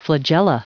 Prononciation du mot flagella en anglais (fichier audio)
Prononciation du mot : flagella